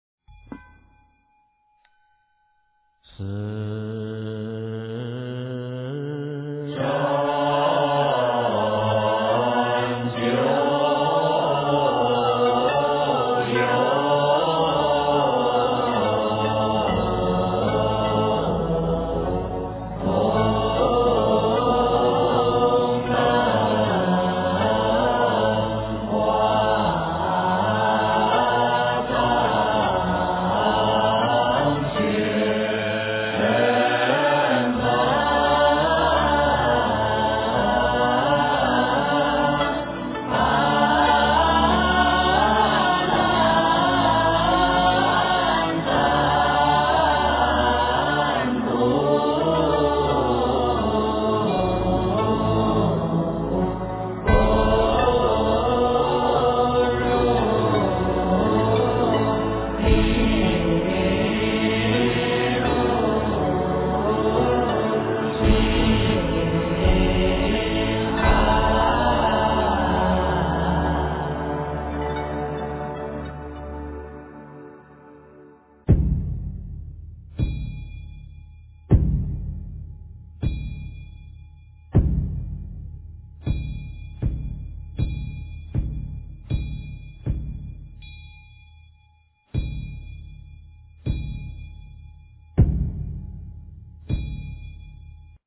四生九有-功课--佛光山梵呗团 经忏 四生九有-功课--佛光山梵呗团 点我： 标签: 佛音 经忏 佛教音乐 返回列表 上一篇： 宝山偈--群星 下一篇： 观音圣号五音调--佛光山 相关文章 三皈依--佛光山梵呗 三皈依--佛光山梵呗...